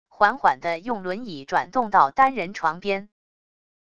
缓缓的用轮椅转动到单人床边wav音频